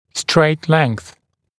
[streɪt leŋθ][стрэйт лэнс]прямой отрезок проволоки для формирования дуги